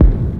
MB Kick (21).wav